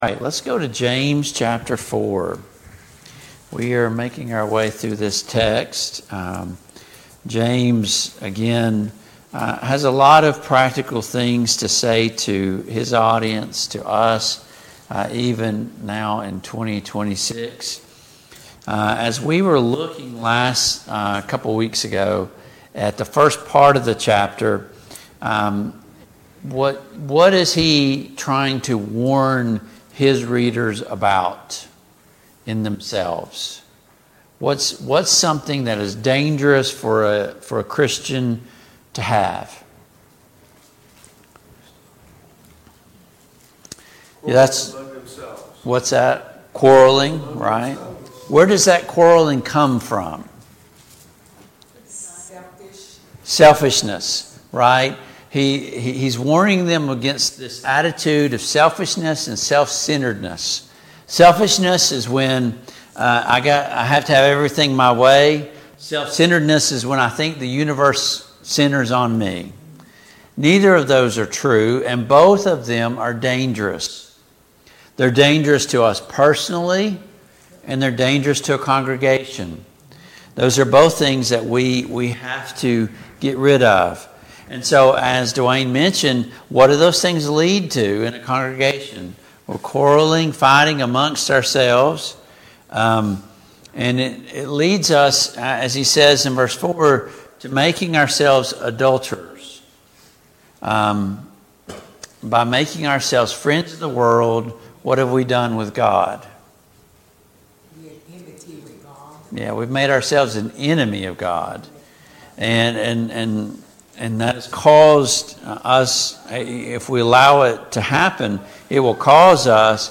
Study of James and 1 Peter and 2 Peter Passage: James 4:1-10 Service Type: Family Bible Hour « How much sin is too much sin?